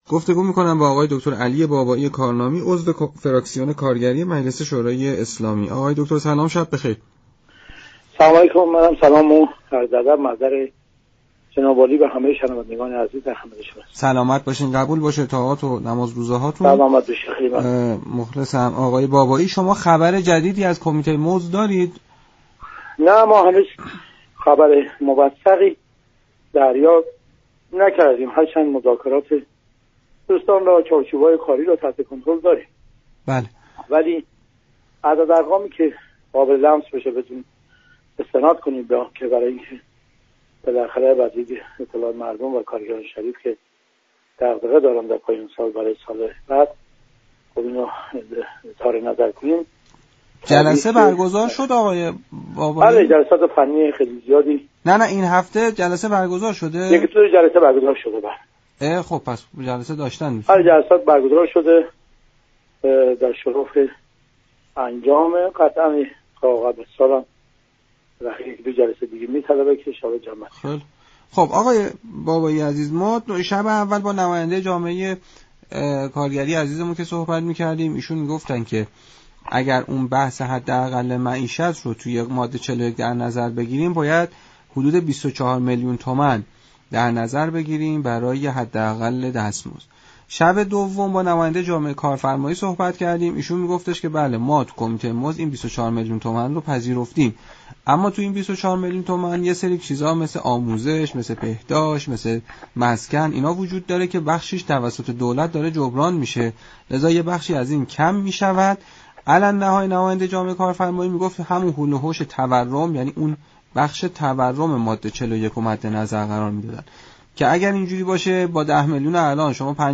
عضو فراكسیون كارگری مجلس شورای اسلامی در برنامه دستوركار گفت: درآمدكارگری كه خانواده 4 تا 5 نفری دارد نباید كمتر از 20 میلیون تومان باشد.